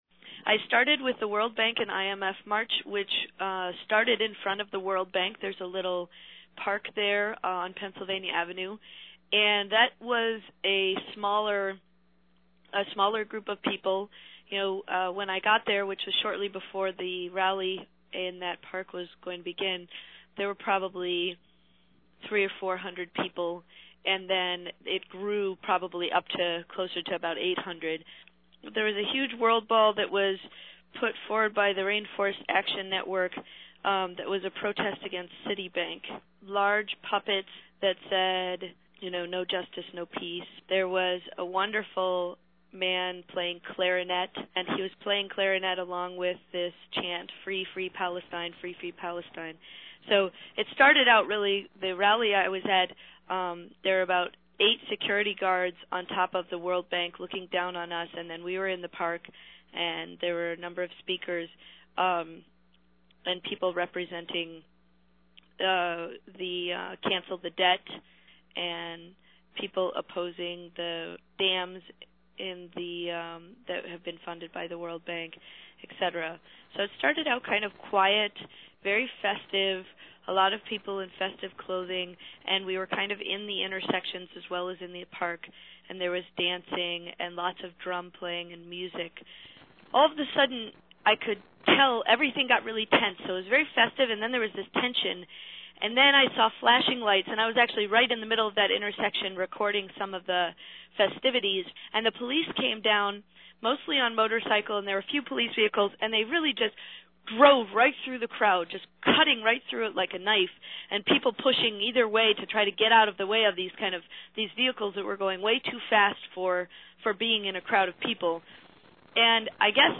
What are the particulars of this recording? As aired on the 4-22 edition of IMC Radio News.